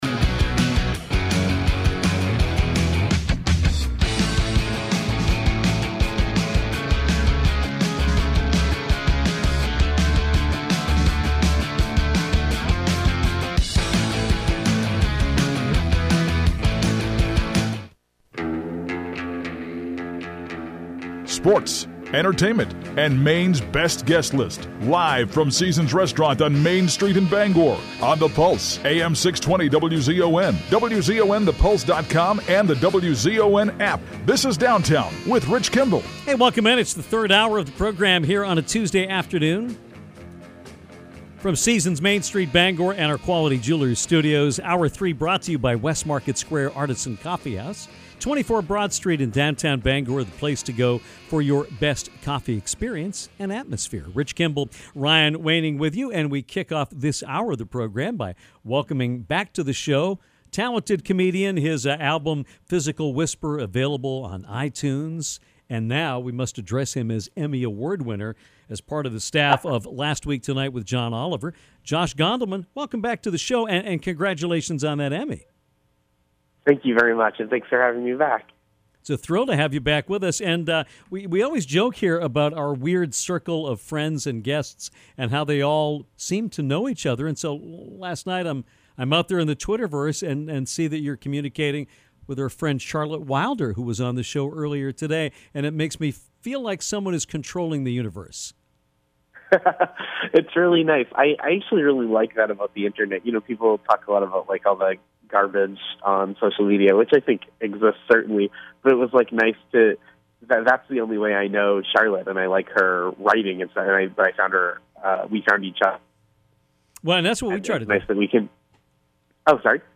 From Last Week Tonight, writer and comedian Josh Gondelman called in to talk about his recent Emmy win as well as to talk about his career in general. Josh lets us know about some people that are worth the follow on Twitter, and laughs with us about LWT’s treatment of Maine Governor Paul Lepage. https